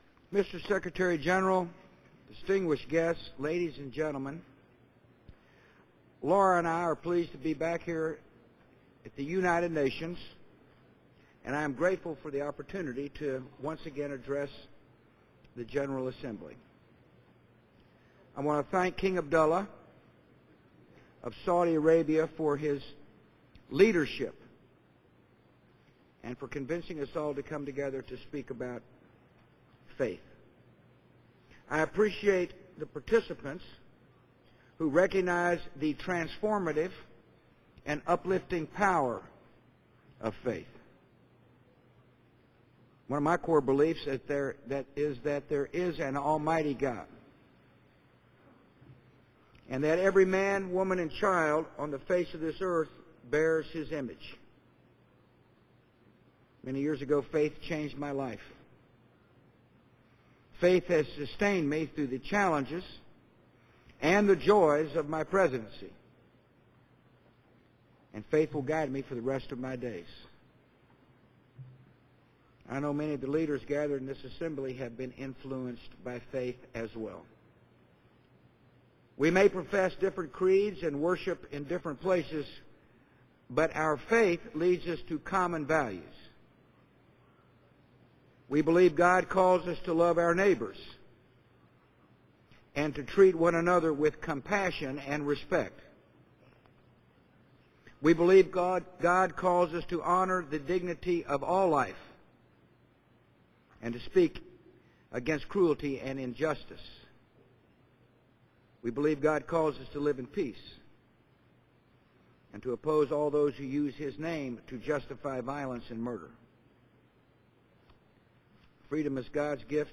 U.S. President George W. Bush speaks about religions